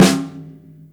• Long Snare Drum Sound G Key 552.wav
Royality free snare single hit tuned to the G note. Loudest frequency: 1354Hz
long-snare-drum-sound-g-key-552-tHM.wav